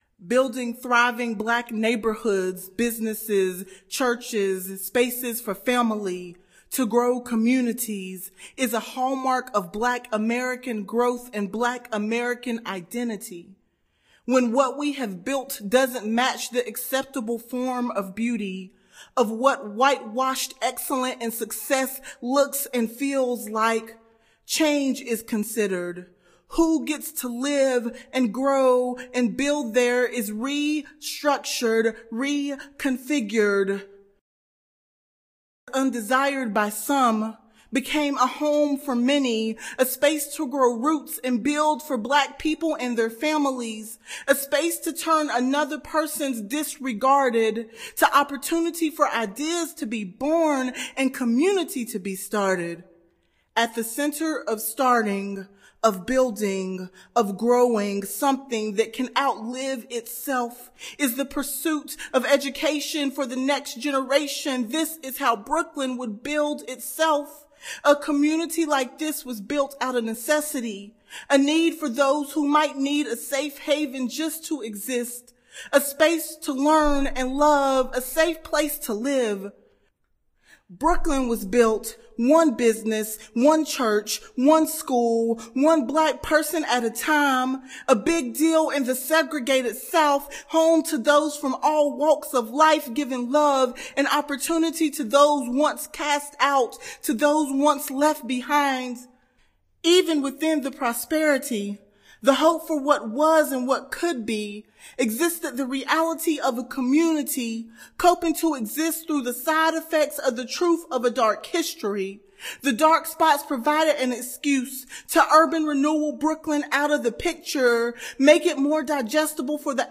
Brooklyn-Story-Poem-Extended.ogg